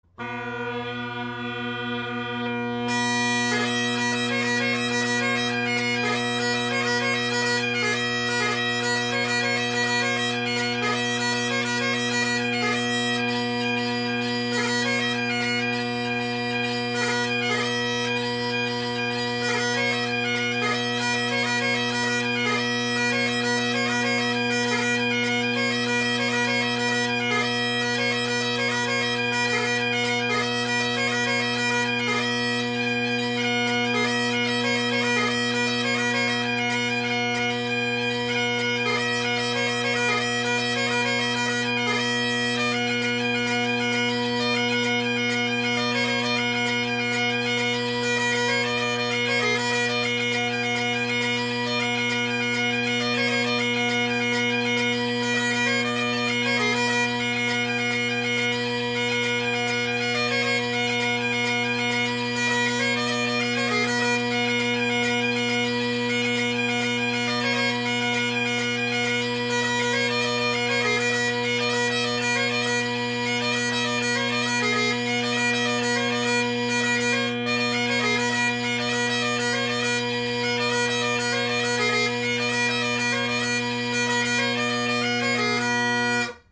Drone Sounds of the GHB, Great Highland Bagpipe Solo
So the audio that follows is of my band set – the drones + these new drones.
Sorry about the sharp F and flat high A, at times, and occasional cut out.
The mic was placed behind me since we’re listening to the drones here (I’m a drone guy, what can I say?).
The bass drone may be a tad quiet, of course Redwood tenor reeds offer a bold tone without the harshness of other bold tenor reeds, so it may be relative.